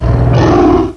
pokeemerald / sound / direct_sound_samples / cries / landorus_therian.aif